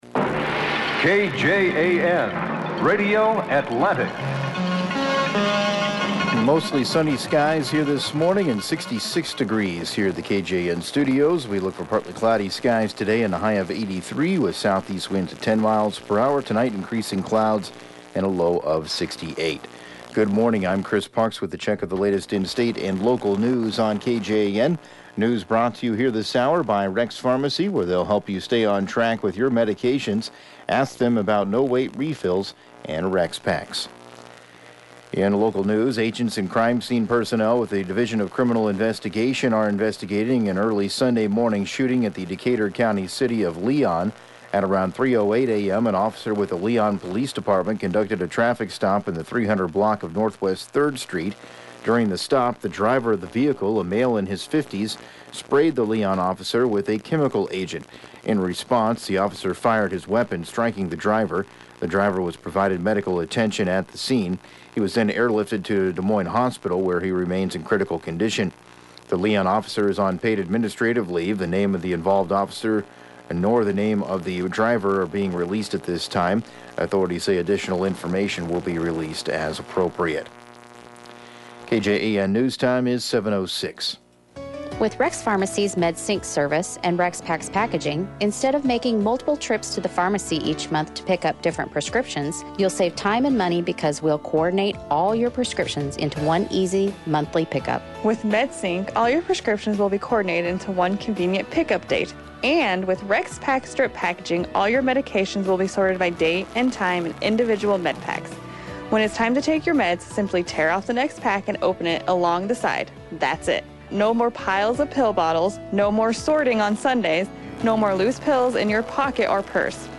7AM Newscast 07/08/2019